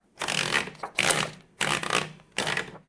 Movimiento del rodillo de una máquina de escribir
máquina de escribir
Sonidos: Oficina